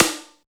TECHTOM HI.wav